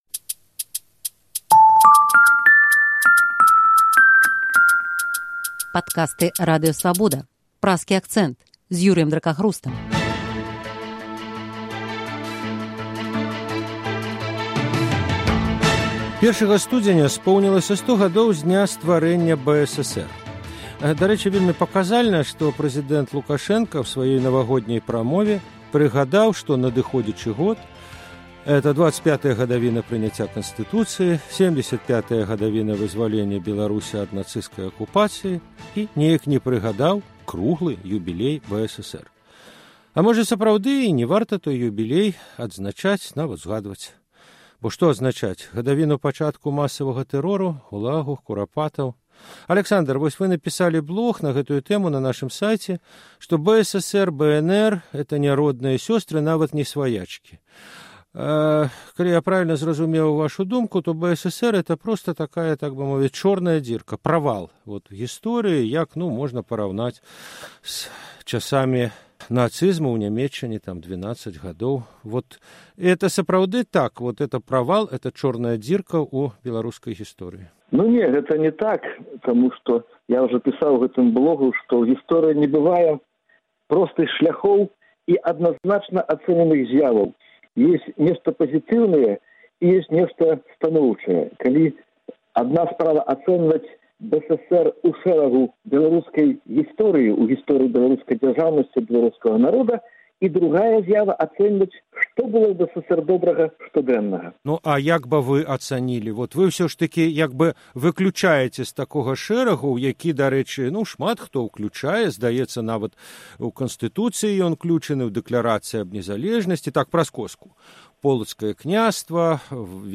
Чаму ўлада не сьвяткуе юбілей БССР? Гэтыя пытаньні ў Праскім акцэнце абмяркоўваюць гісторыкі